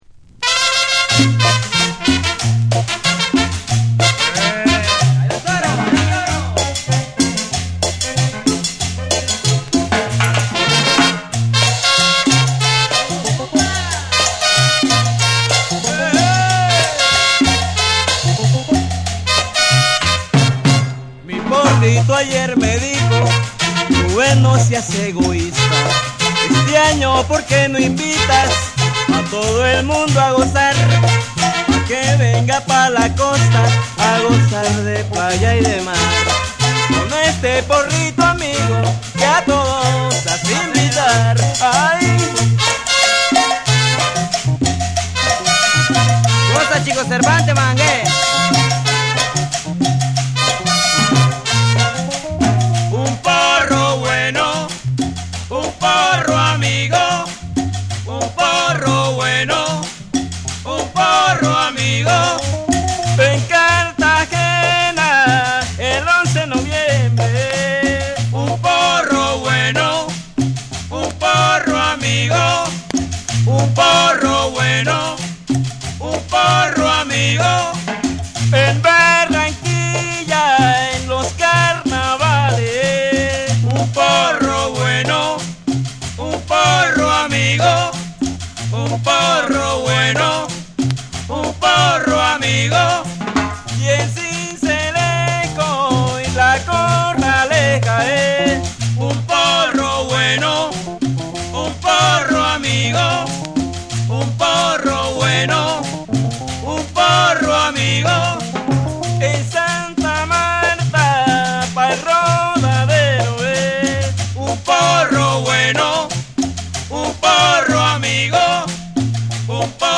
陽気でカラフルなリズム、伸びやかなホーンやギターが絡み合うトロピカル・サウンドが全編を彩る一枚。
WORLD